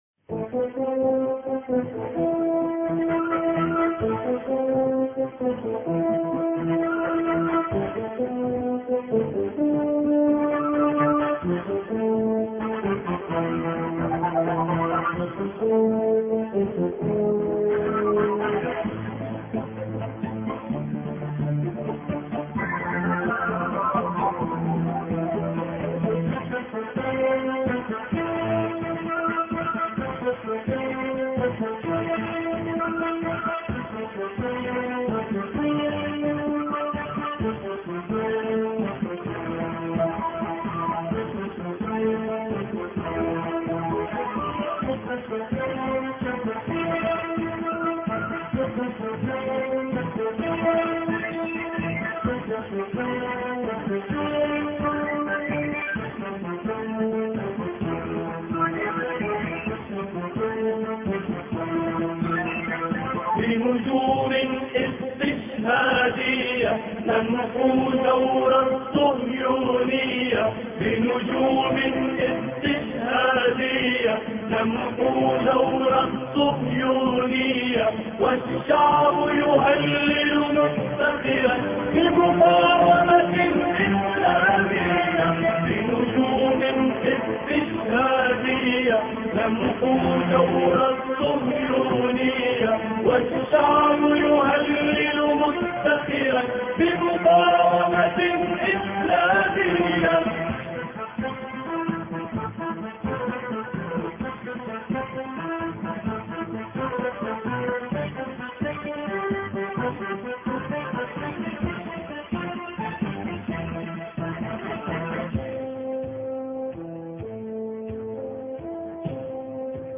بنجوم استشهادية الأحد 16 مارس 2008 - 00:00 بتوقيت طهران تنزيل الحماسية شاركوا هذا الخبر مع أصدقائكم ذات صلة الاقصى شد الرحلة أيها السائل عني من أنا..